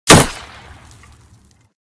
/ gamedata / sounds / material / bullet / collide / wood04gr.ogg 22 KiB (Stored with Git LFS) Raw History
wood04gr.ogg